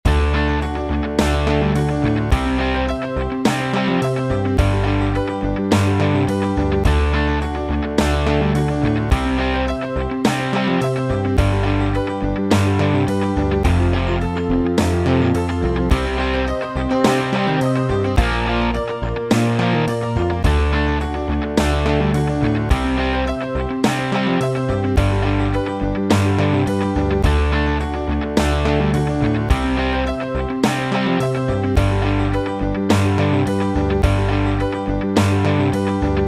JamStudio is a nifty online music mixer where you can compose and publish your own tunes.